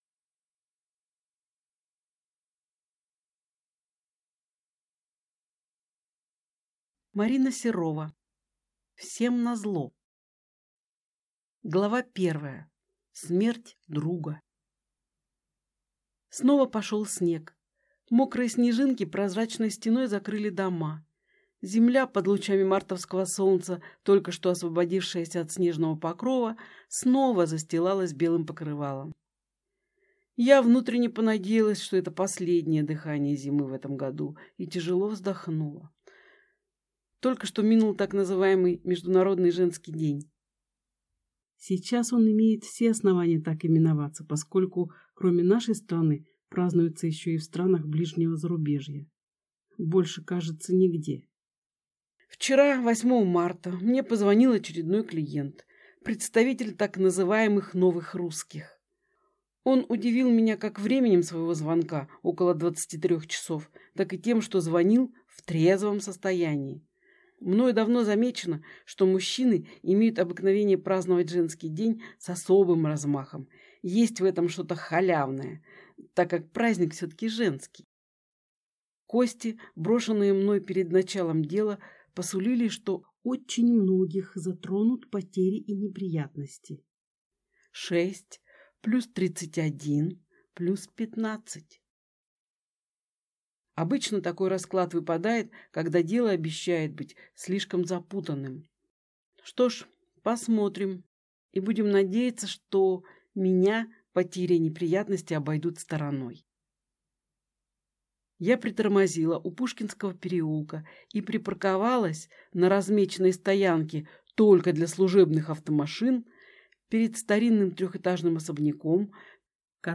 Аудиокнига Всем назло | Библиотека аудиокниг
Прослушать и бесплатно скачать фрагмент аудиокниги